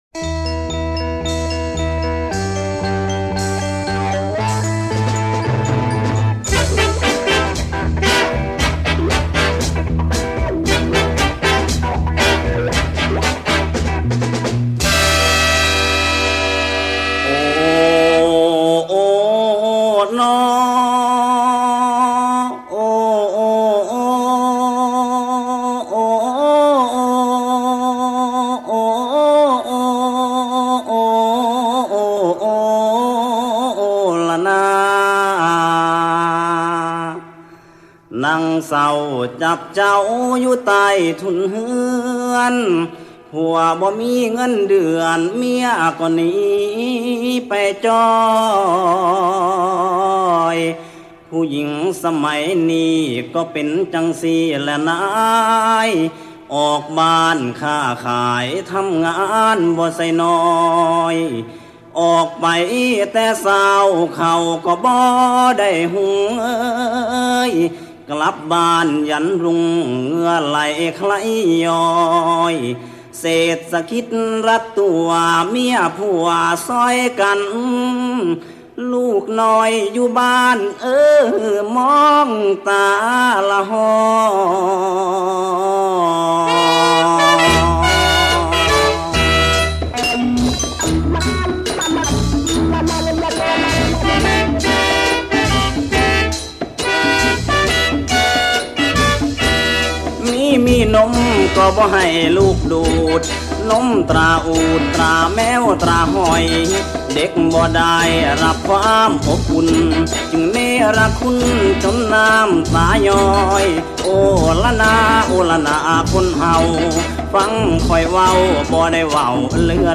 Leftfield Luk Thung, Jazz & Molam in Thailand 1964-1975